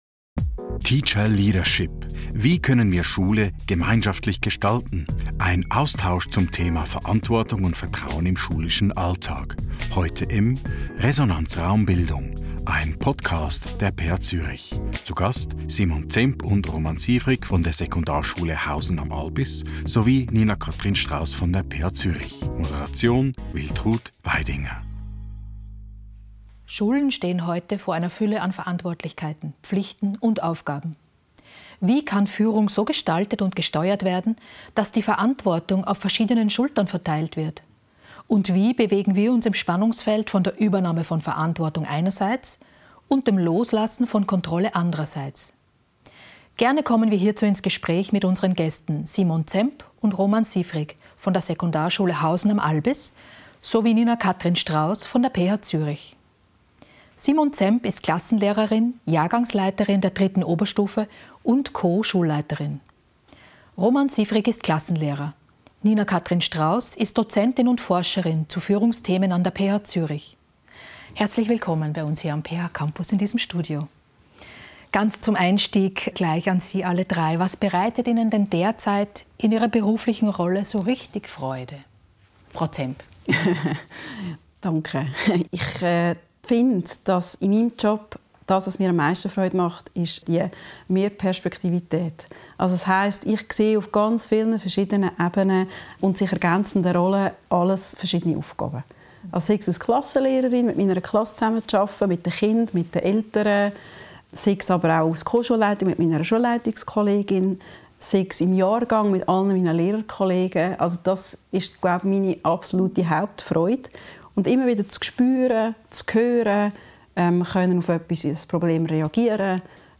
Wie kann Führung so gestaltet und gesteuert werden, dass die Verantwortung auf verschiedenen Schultern verteilt wird? Und wie bewegen wir uns im Spannungsfeld von der Übernahme von Verantwortung einerseits und dem Loslassen von Kontrolle andererseits? Im Gespräch